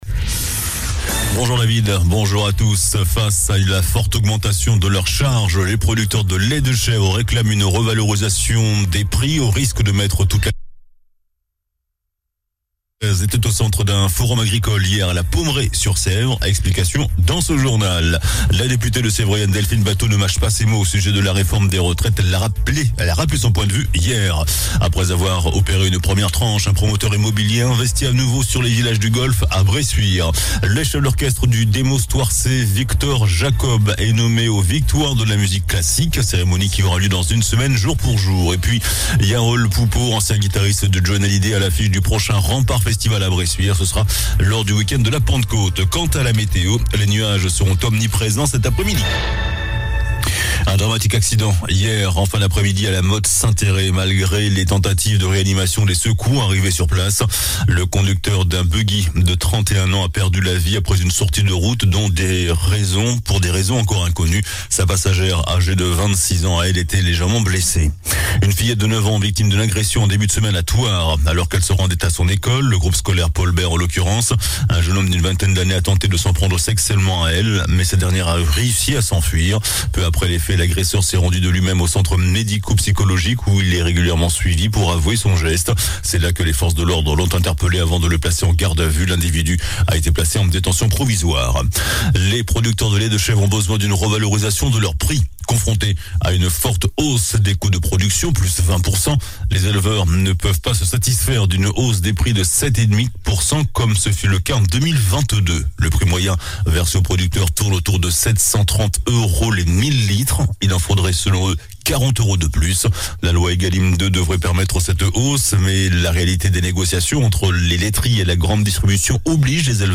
JOURNAL DU MERCREDI 22 FEVRIER ( MIDI )